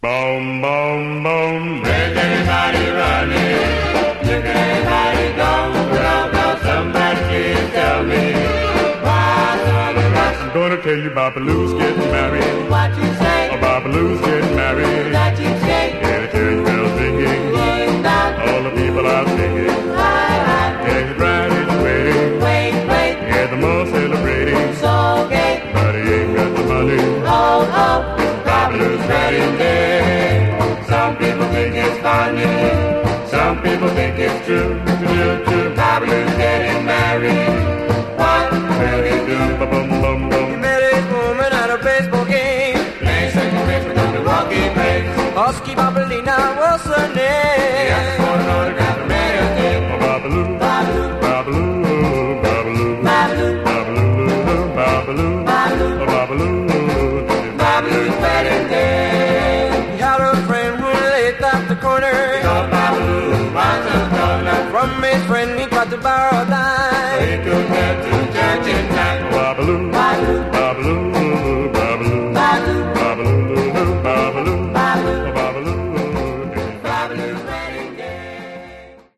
Genre: Vocal Groups (Doo-Wop)